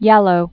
(yălō), Rosalyn Sussman 1921-2011.